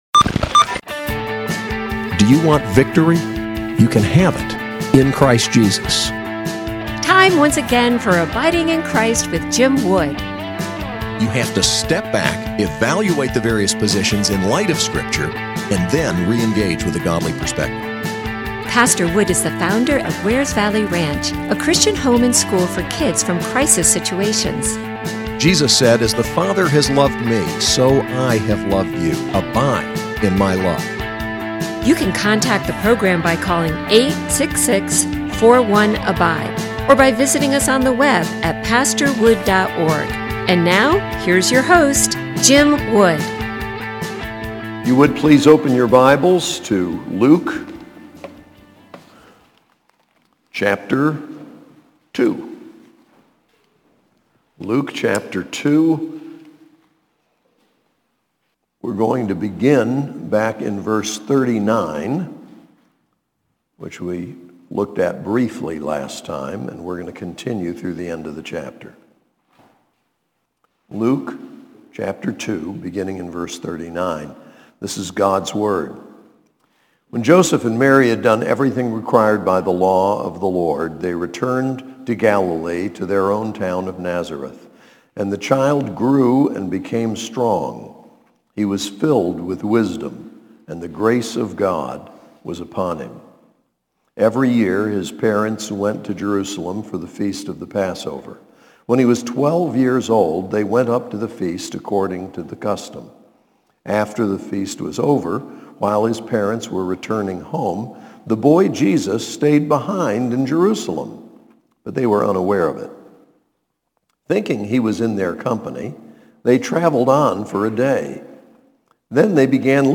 SAS Chapel: Luke 2:39-52